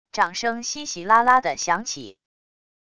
掌声稀稀拉拉的响起wav音频